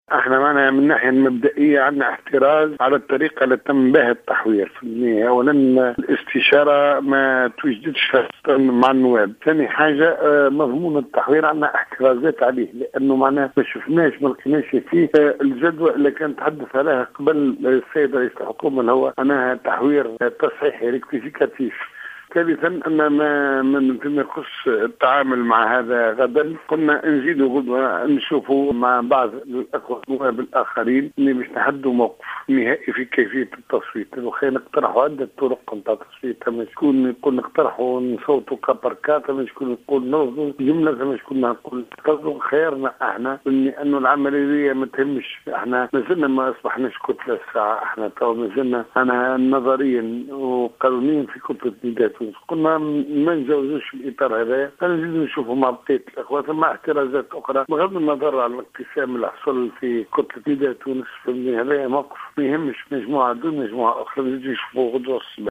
أكد عضو مجلس نواب الشعب مصطفى بن أحمد في تصريح للجوهرة "اف ام" مساء الأحد أن مجموعة النواب المستقيلين عن كتلة نداء تونس لم تتوصل إلى اتخاذ قرار بشأن منح الثقة للحكومة بتركيبتها الجديدة خلال الجلسة العامة لمجلس نواب الشعب غدا الإثنين.